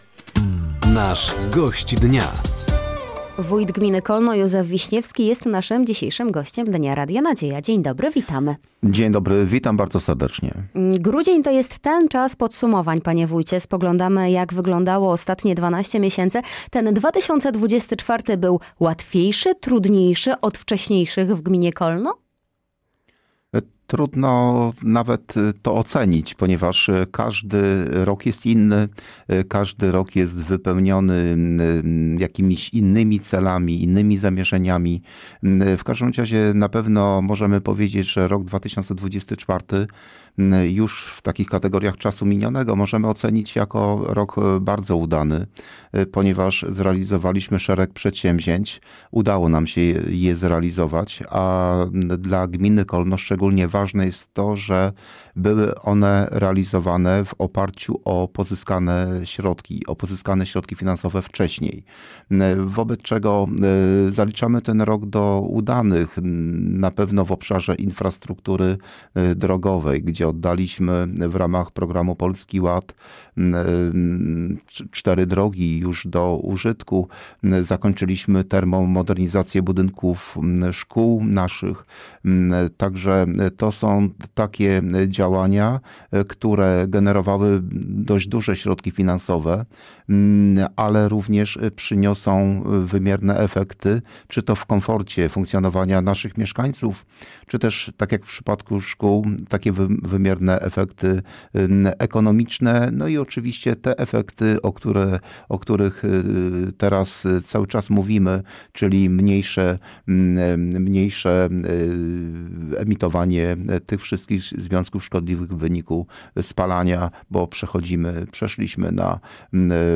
Studio odwiedził wójt gminy Kolno, Józef Wiśniewski.